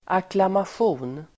Ladda ner uttalet
acklamation substantiv, acclamation Uttal: [aklamasj'o:n] Böjningar: acklamationen Definition: allmänt bifall (general demonstration of approval) Exempel: väljas med acklamation (be voted by (with) acclamation)